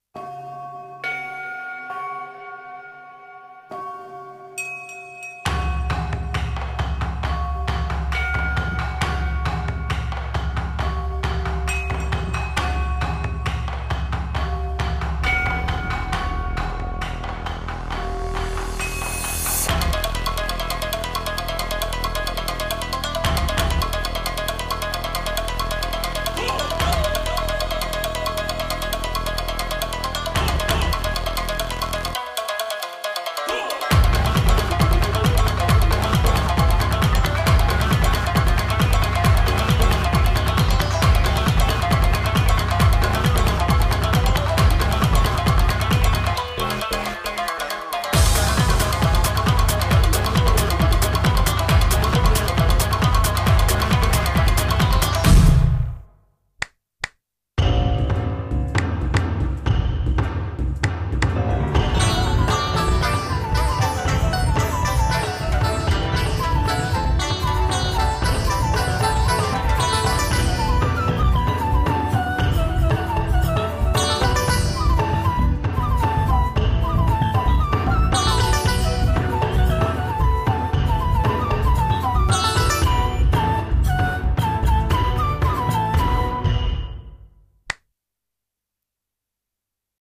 CM風声劇「妖町の菓子売